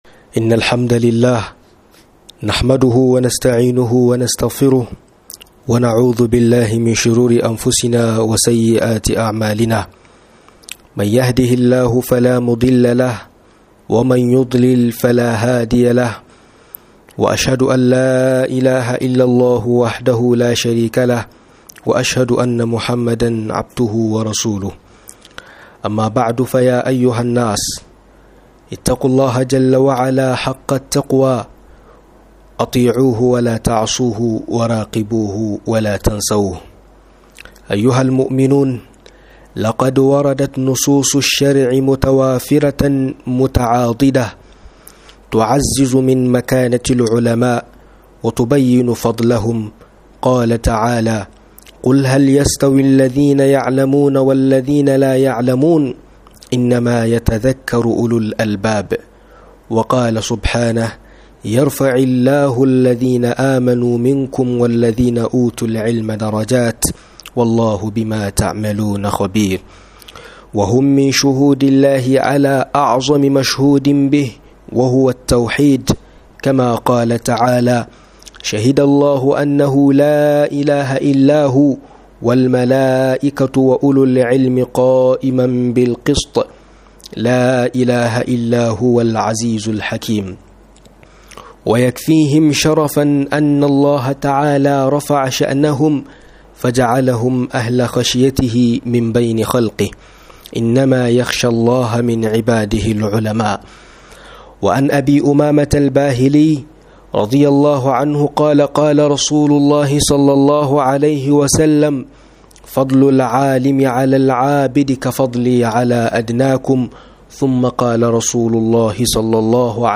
Mastayin Malamay acikin Al-umma - MUHADARA